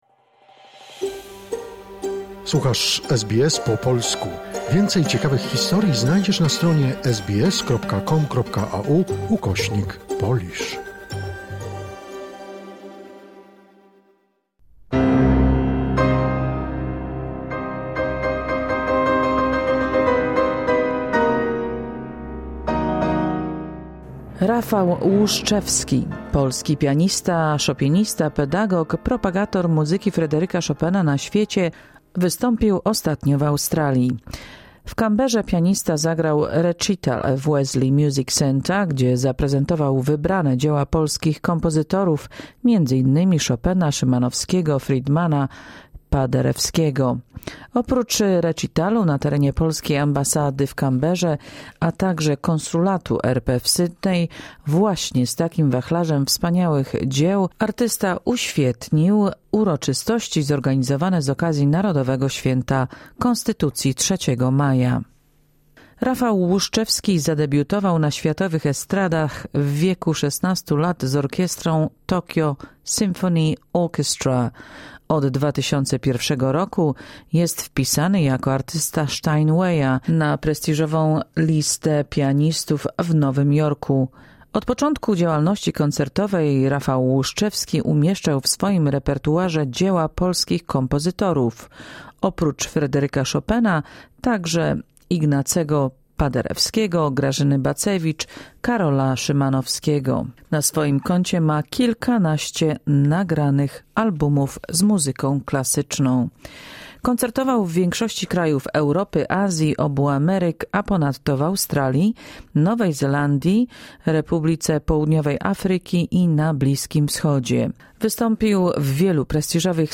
Część druga rozmowy z artystą.